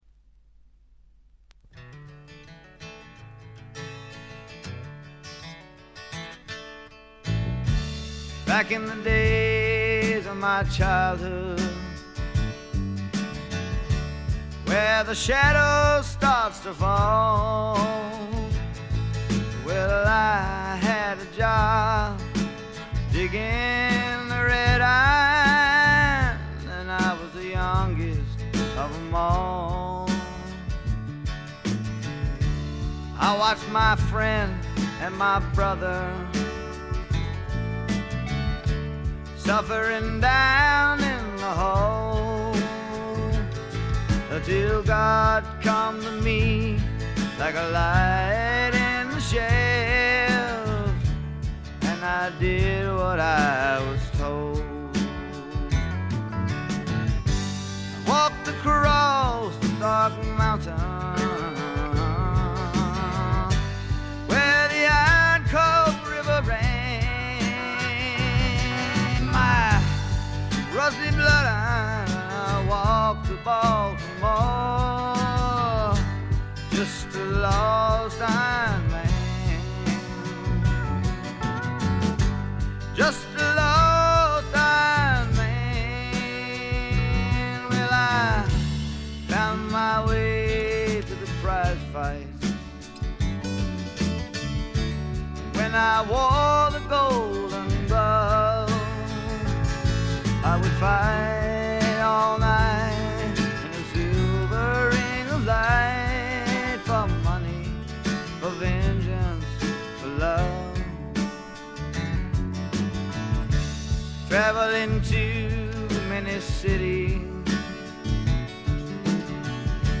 シンガーソングライター名盤中の名盤。
聴くものの心をわしづかみにするような渋みのある深いヴォーカルは一度聴いたら忘れられません。
試聴曲は現品からの取り込み音源です。